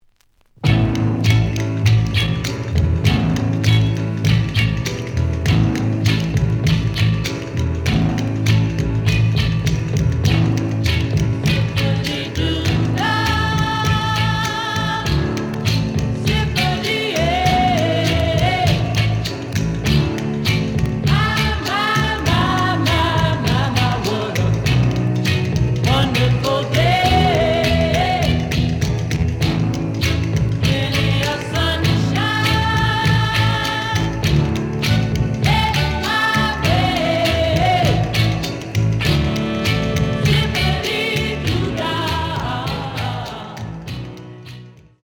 試聴は実際のレコードから録音しています。
●Genre: Rhythm And Blues / Rock 'n' Roll
●Record Grading: EX- (盤に若干の歪み。多少の傷はあるが、おおむね良好。)